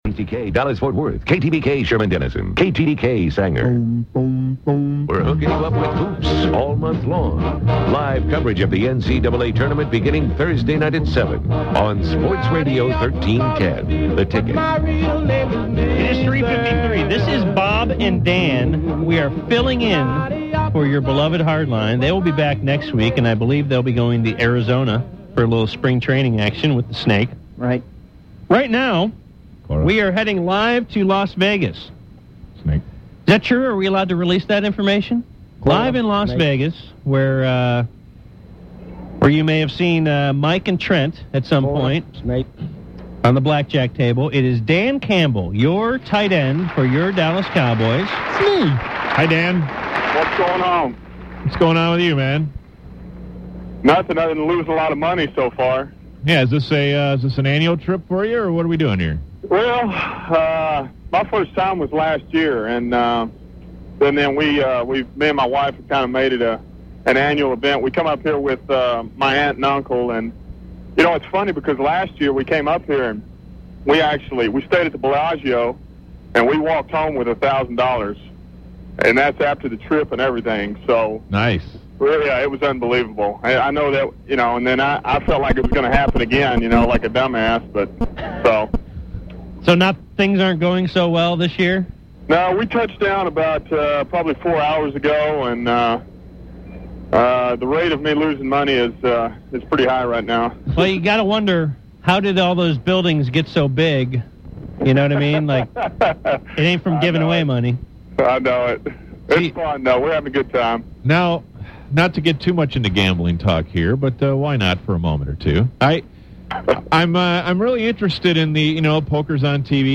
Dan Campbell Interview 3-16-04 - The UnTicket
Former Cowboy great Dan Campbell on with the Bad Radio boyz